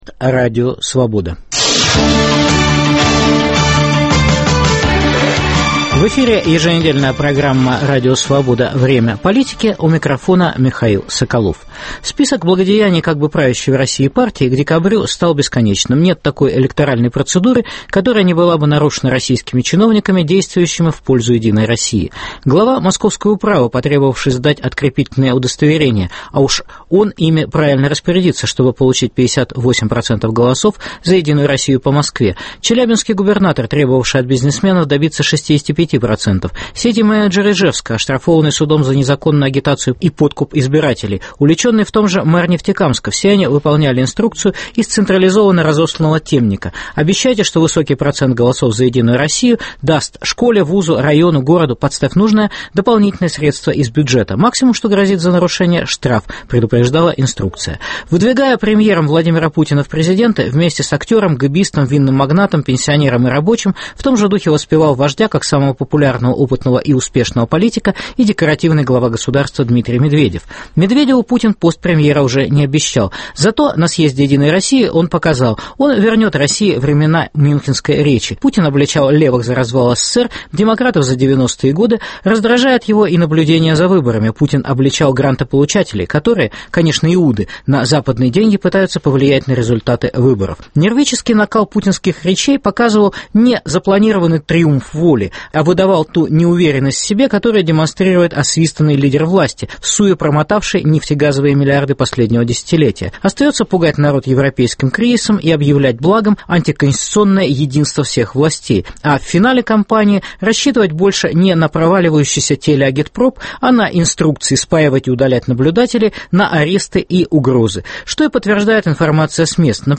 Что делать 4 декабря, если "выборы" – это не выборы. Дискутируют политики и политологи - Александр Подрабинек, Георгий Сатаров, Аркадий Мурашев, Дмитрий Орешкин, Михаил Касьянов.